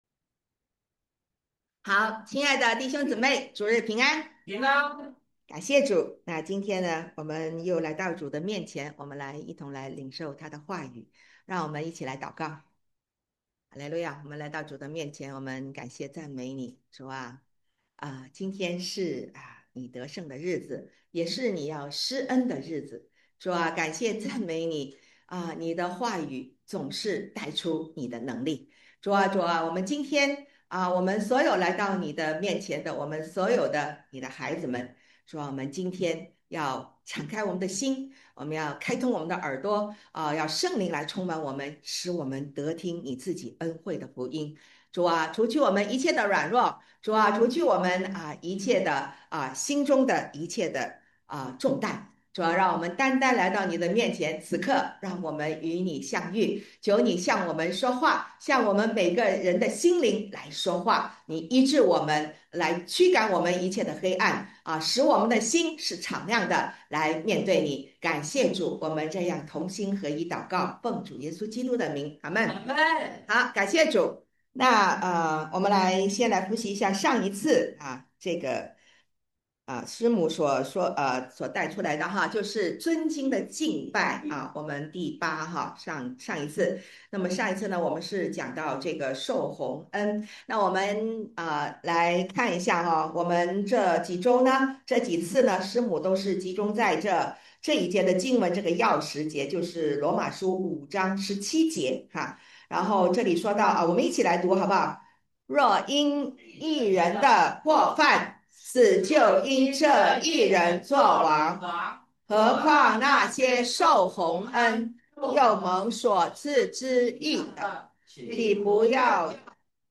讲道录音